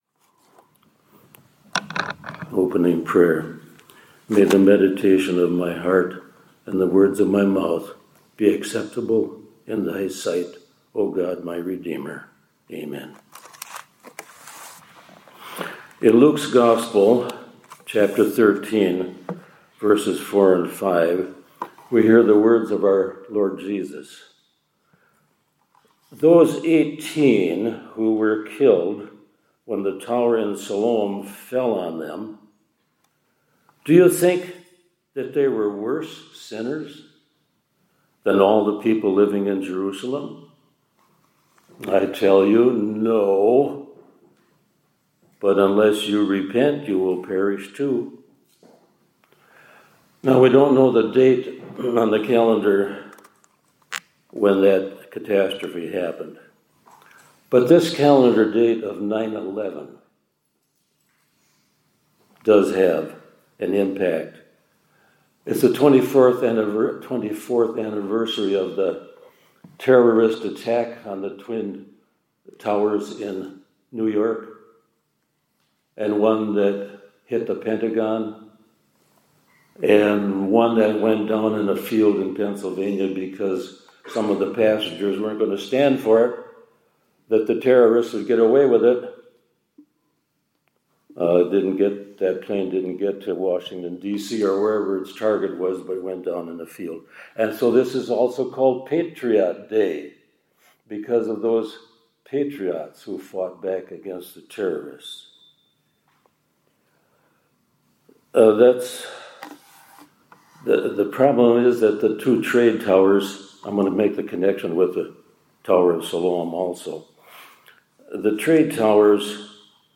2025-09-11 ILC Chapel — God’s Bolt From the Blue